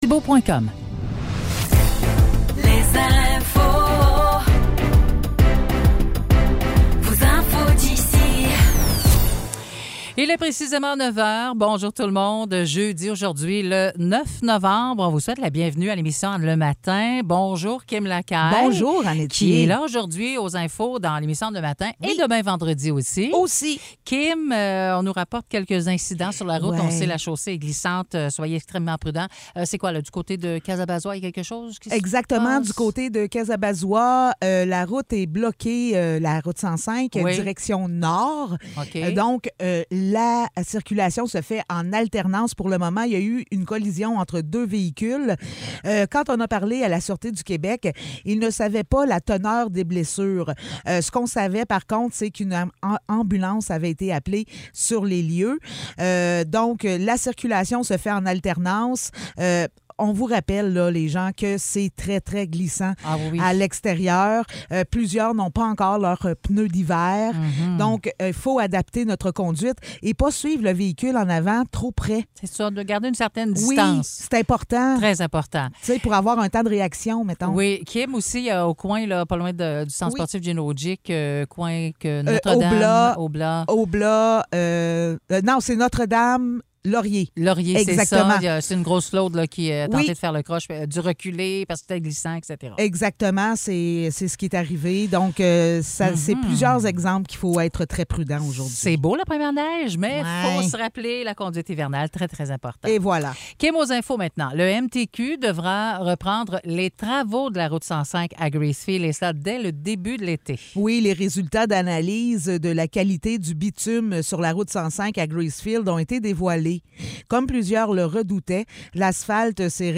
Nouvelles locales - 9 novembre 2023 - 9 h